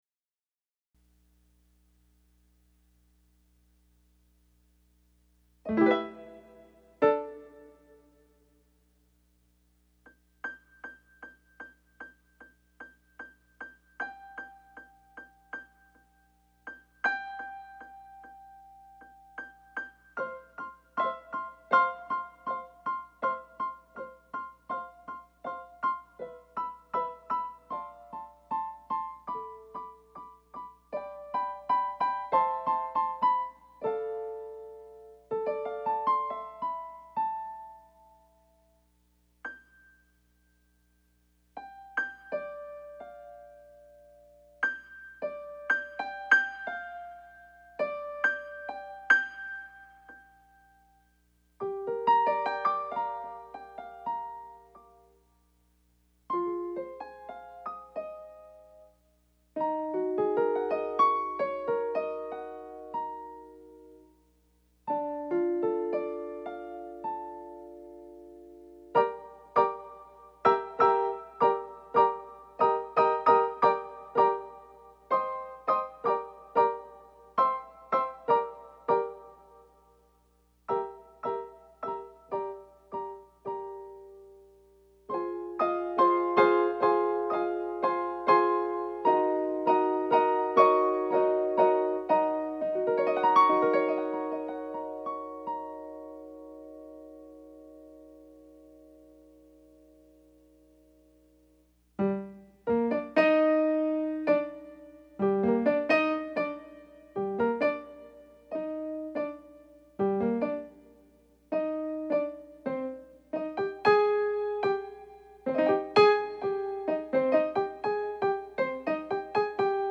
Toujours enregistré  »Live »  Do dièse,  Sol min et autres excursions au fil de l’intuition.
Pièce jouée en style cut-up,